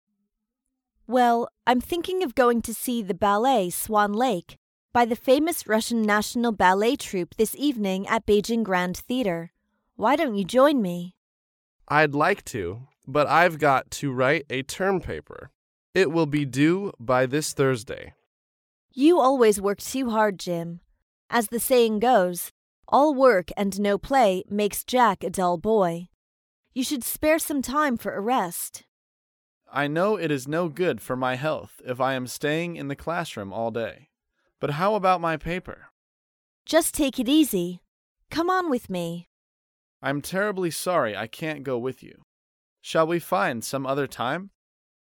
英语情景对话